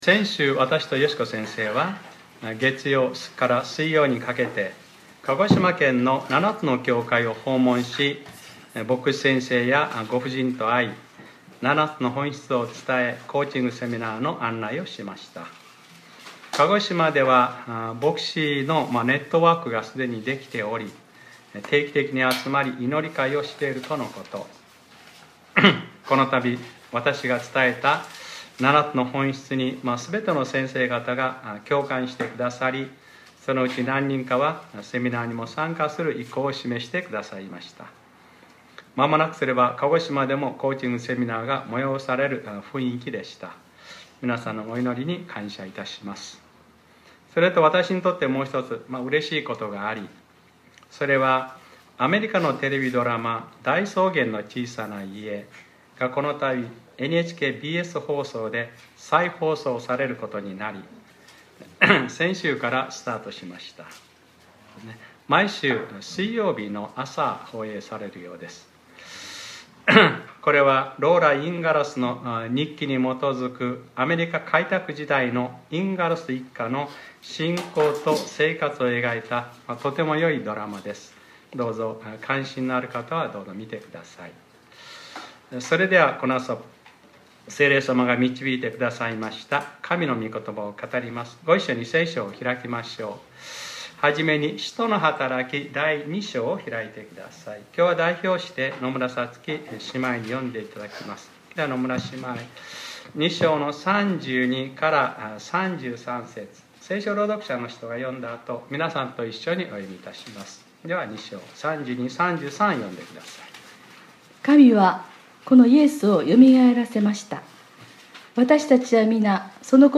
2019年06月09日（日）礼拝説教『パラクレイトス』